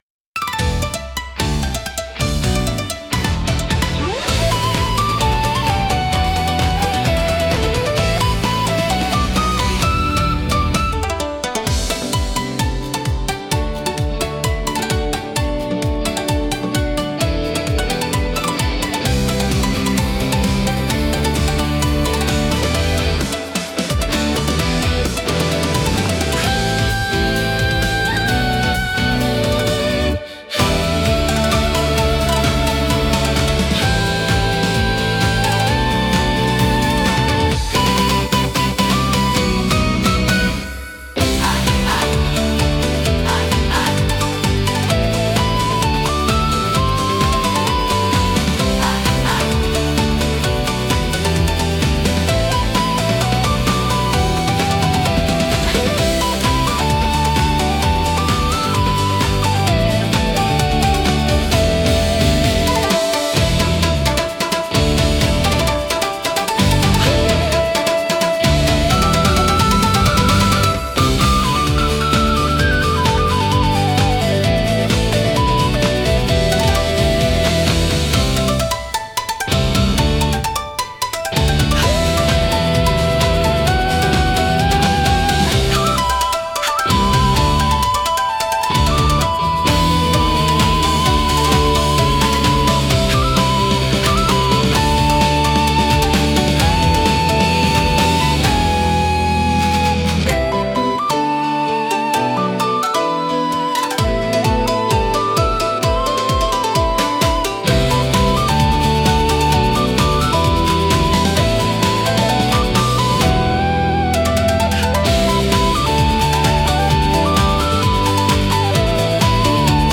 煌びやかなメロディが場を一気に華やがせ、疾走感のあるビートが聴く人のテンションを最高潮へと導きます。
• ジャンル： 和風ポップ / 和モダン / インストゥルメンタル / ロック
• 雰囲気： 華やか / 疾走感 / 凛とした / 情熱的 / 煌びやか
• テンポ（BPM）： ファスト（躍動感あふれる速さ）